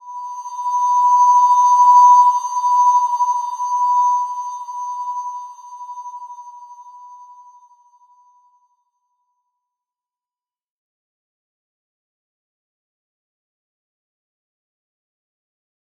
Slow-Distant-Chime-B5-f.wav